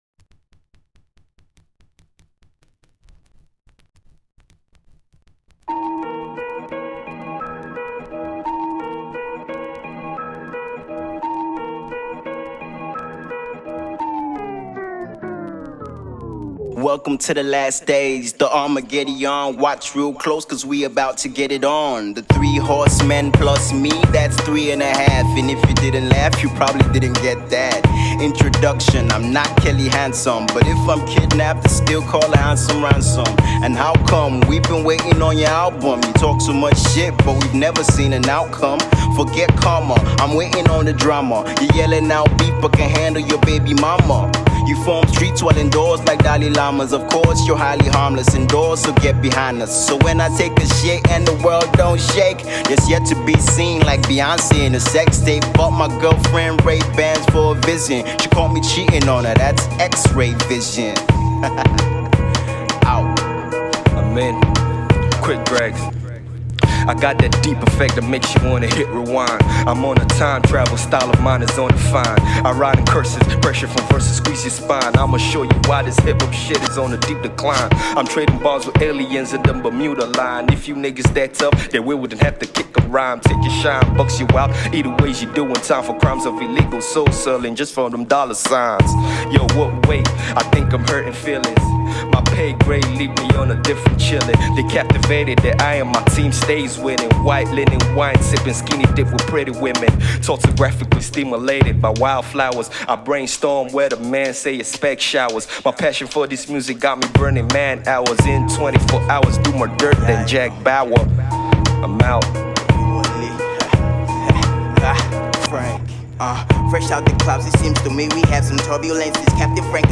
production and gave it an extra hefty bounce
For the Hip-Hop heads!!!
WARNING! explicits lyrics